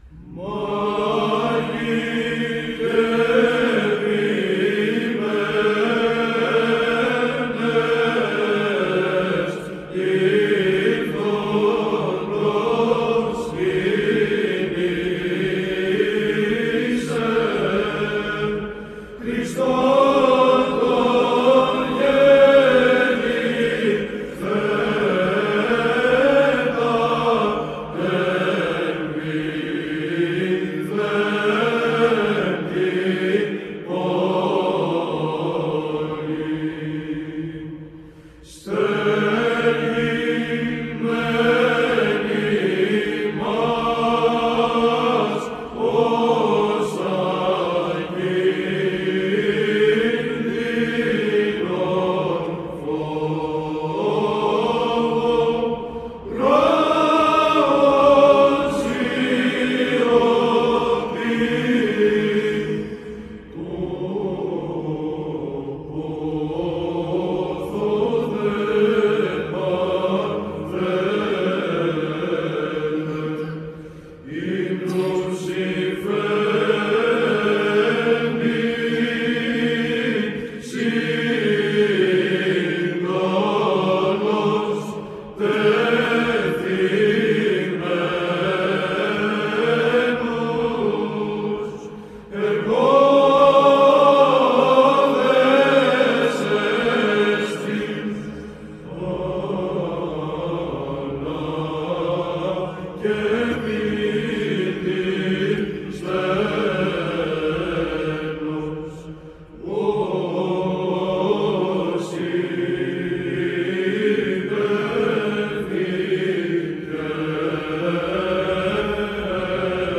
ΒΥΖΑΝΤΙΝΗ ΧΟΡΩΔΙΑ ΕΡΕΥΝΗΤΙΚΟΥ ΩΔΕΙΟΥ ΧΑΛΚΙΔΟΣ
Ψάλλει η Β.Χ.Ε.Ω. Χαλκίδος
ΤΙΤΛΟΣ:  Στέργειν μεν ημάς... Ειρμός Θ΄ Ωδής Καταβασιών.
ΗΧΟΣ:   Πρώτος.